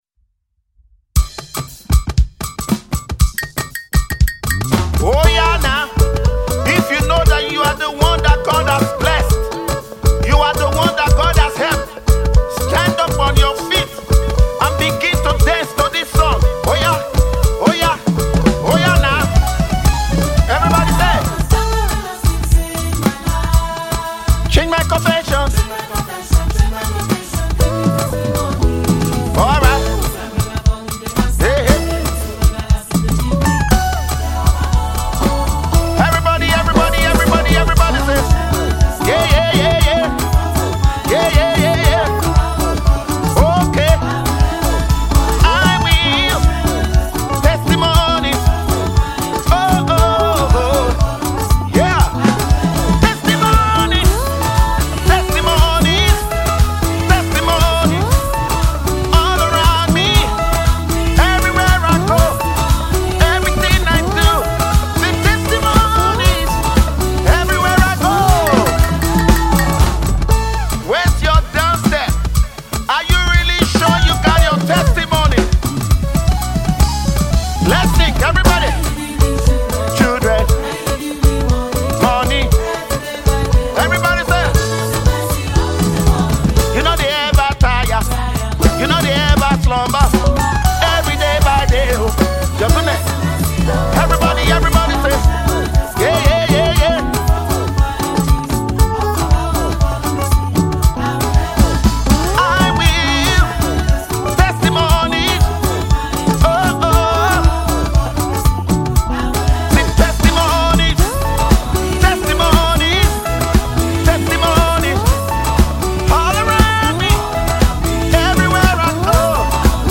prophetic dance praise and worship sound